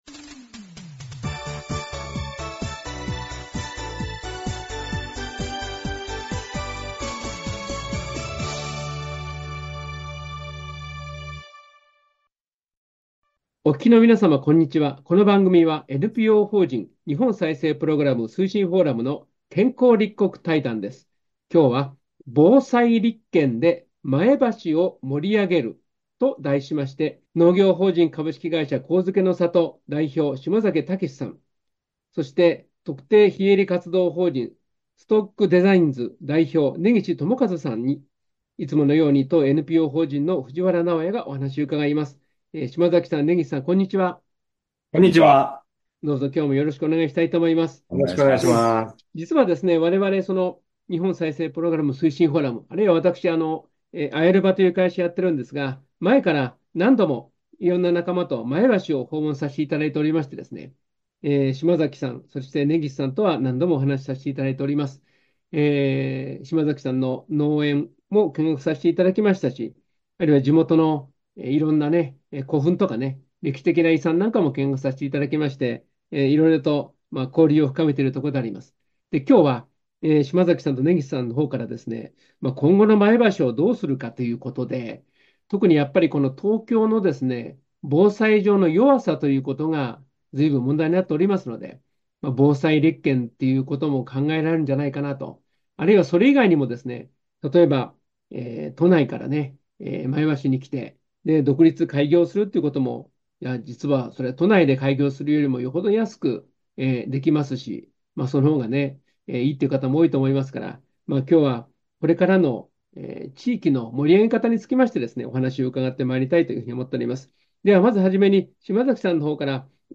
健康立国対談
「防災立県で前橋を盛り上げる」 音声はこちらからお聴きください。 この対談は、2024年11月23日（土）に遠隔収録をしました。